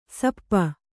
♪ sappa